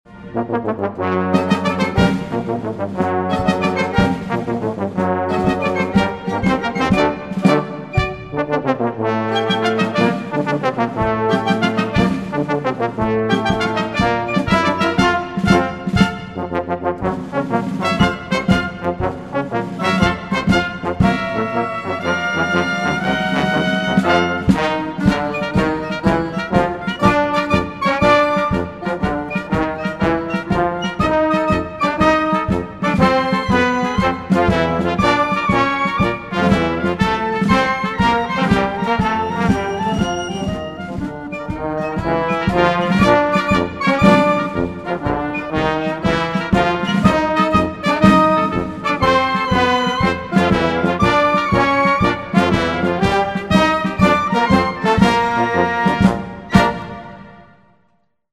Perfect for your Military, Patriotic, or Nautical event!